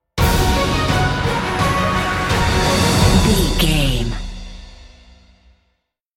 Uplifting
Ionian/Major
orchestral
brass
cello
percussion
strings